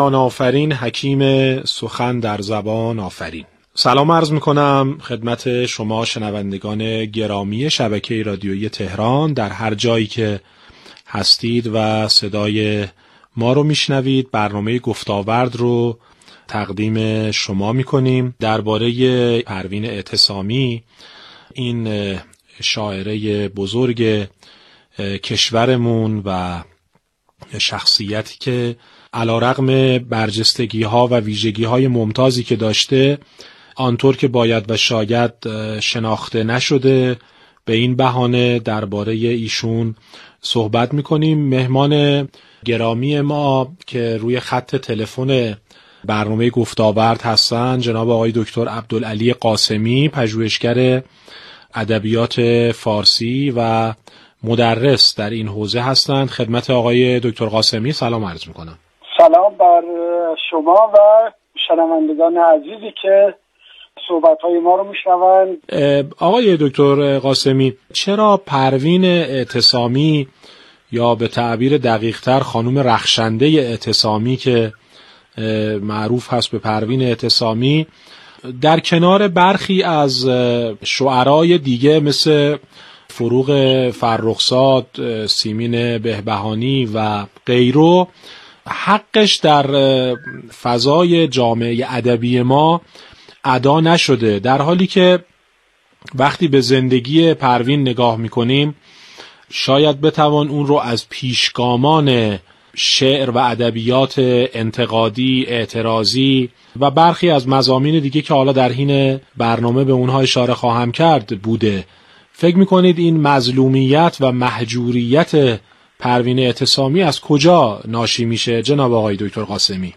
نخستین برنامه گفتاورد رادیو تهران در سال جدید به مناسبت سالروز گرامی داشت پروین اعتصامی به این موضوع اختصاص یافت که چرا پروین اعتصامی انطورکه باید و شاید شناخته شده نیست و نقش و منزلت او در میان جریانهای روشنفکری تحلیل نشده است ؟ مشروح این گفتگو را در لینک زیر بشنوید.